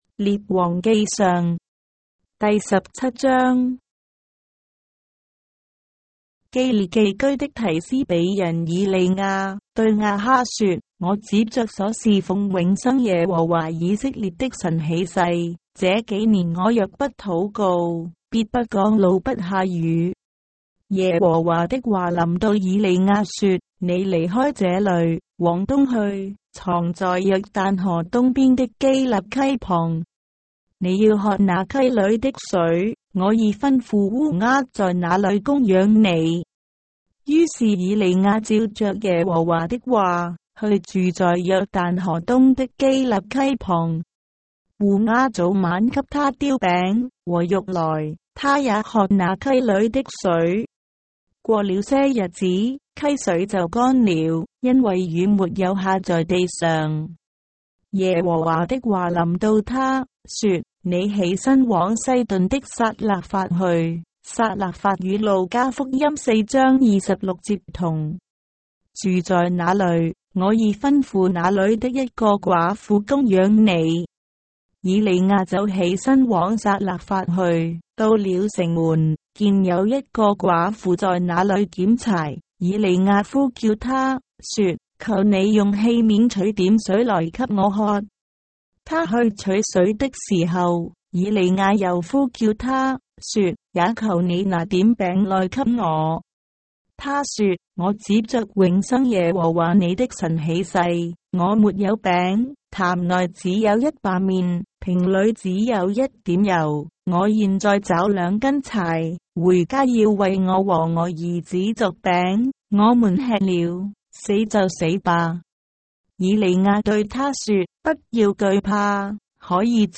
章的聖經在中國的語言，音頻旁白- 1 Kings, chapter 17 of the Holy Bible in Traditional Chinese